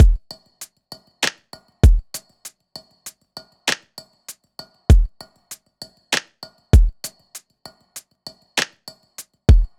Index of /99Sounds Music Loops/Drum Loops/Hip-Hop